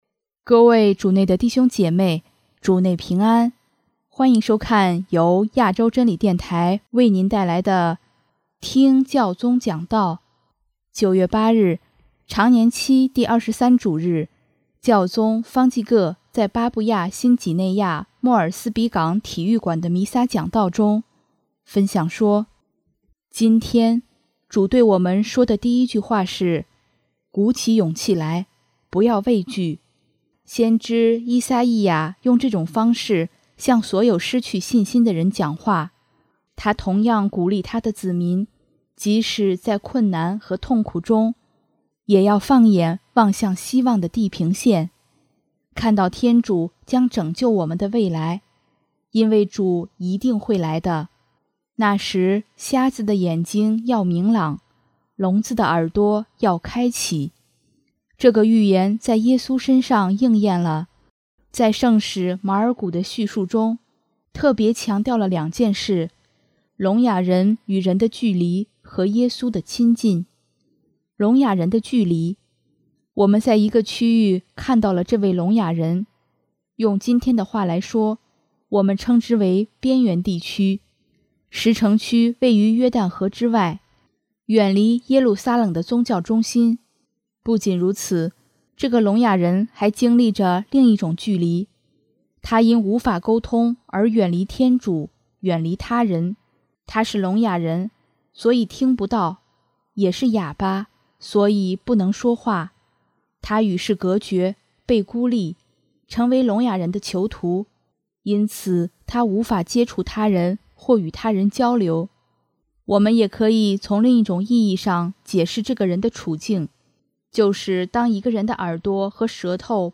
【听教宗讲道】|天主是我们人生的指南针
9月8日，常年期第二十三主日，教宗方济各在巴布亚新几内亚莫尔斯比港体育馆